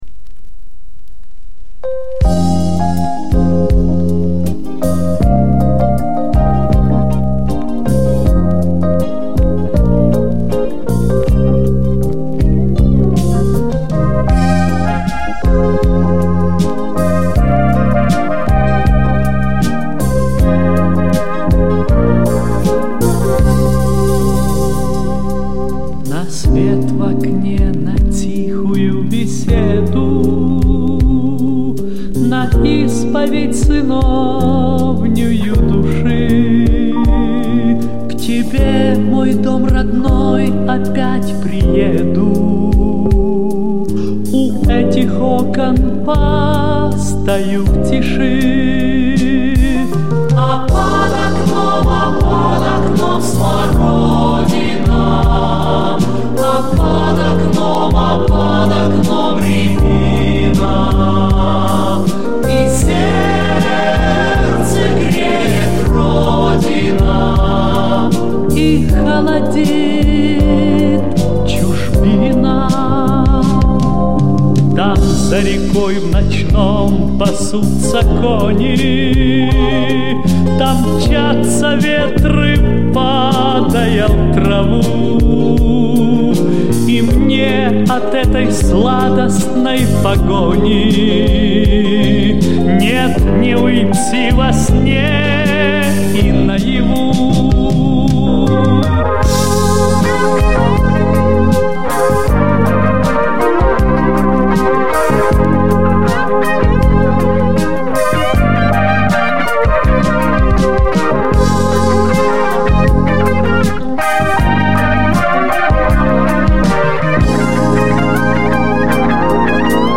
ОЦИФРОВКА С ПЛАСТИНКИ